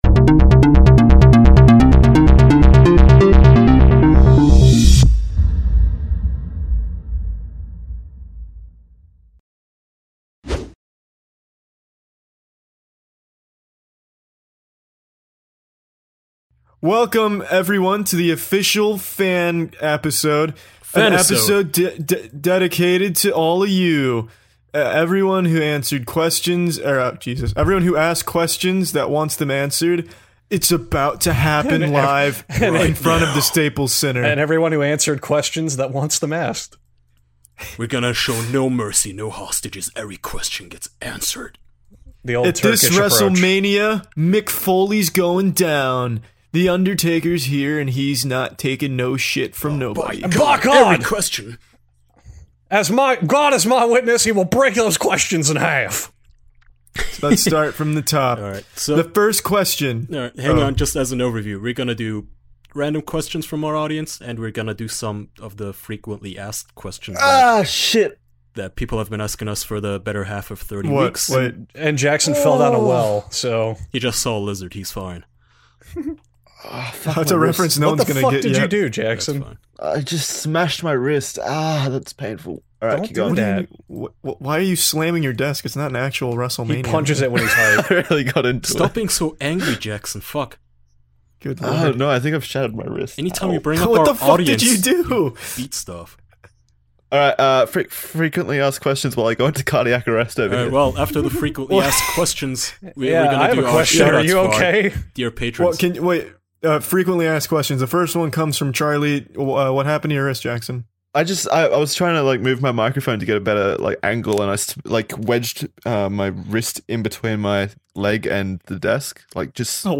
Four close man friends gather around to answer questions, just like the old times.